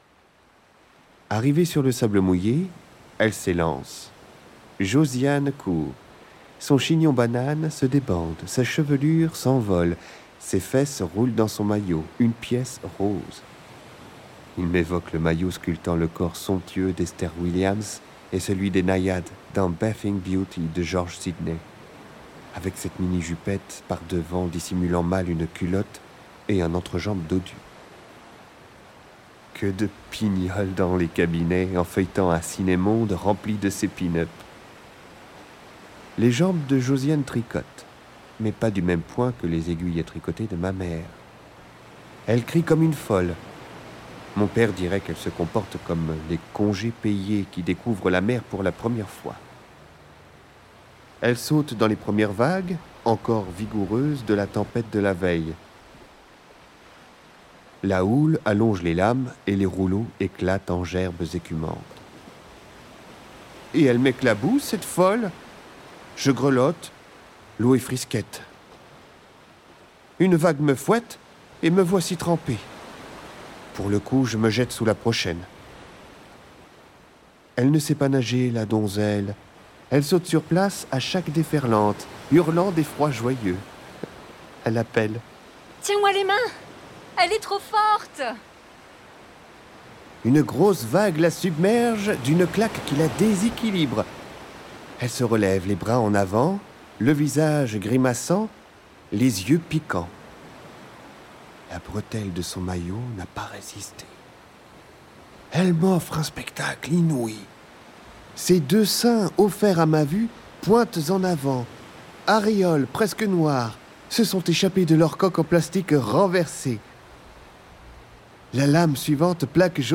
Extrait au format MP3